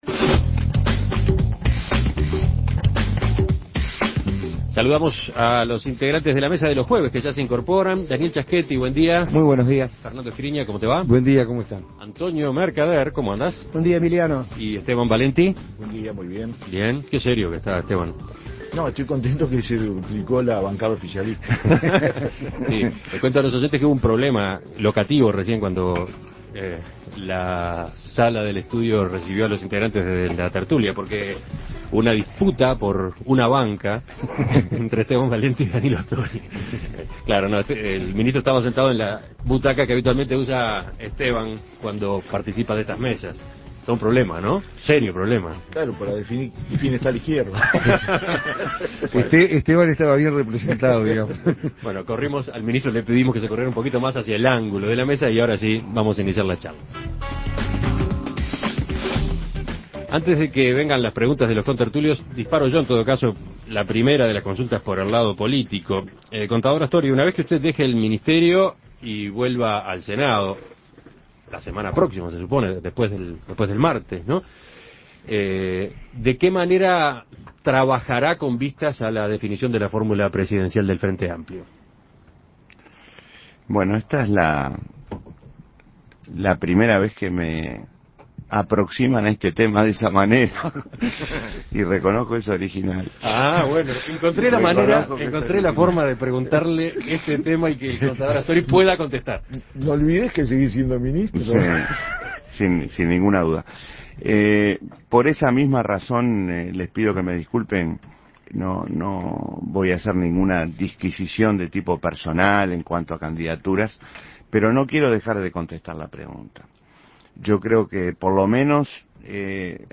Tras la entrevista, el ministro de Economía, Danilo Astori, participó en La Tertulia y habló, entre otros temas, de su eventual candidatura a la Presidencia de la República